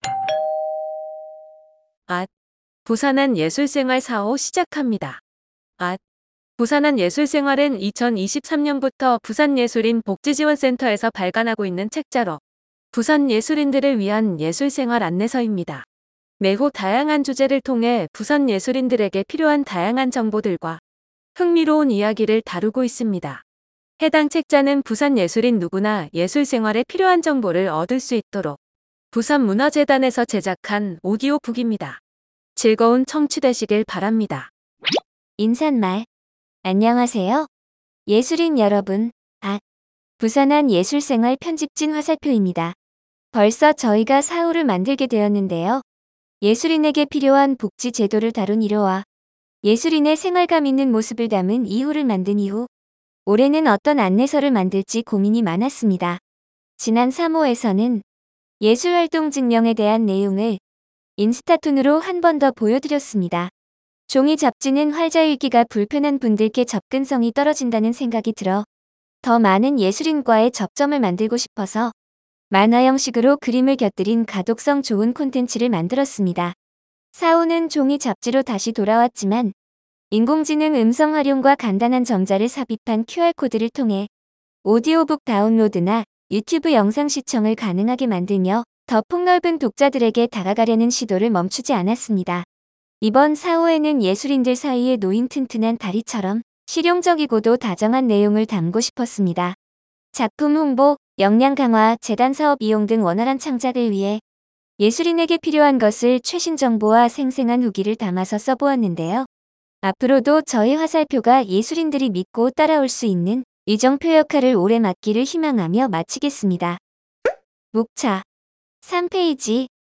예술인 생활 안내서 4호. 오디오북(상)